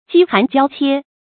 饥寒交切 jī hán jiāo qiē
饥寒交切发音